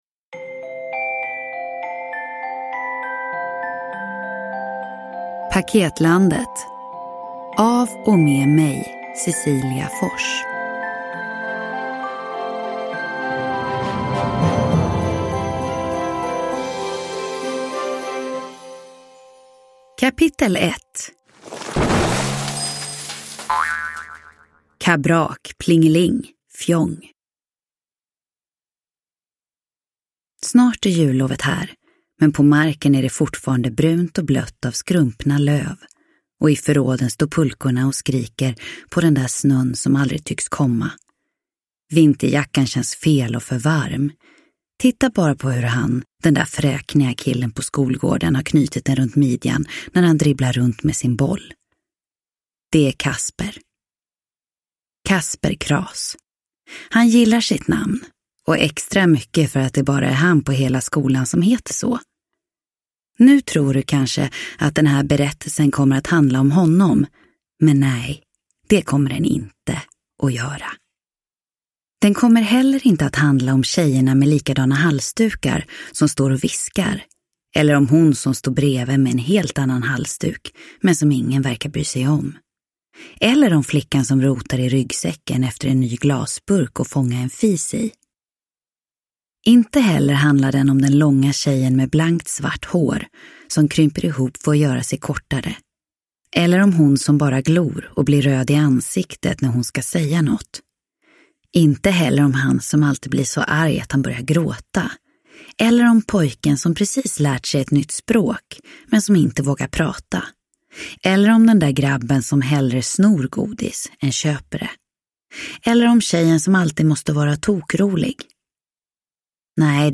Paketlandet – Ljudbok – Laddas ner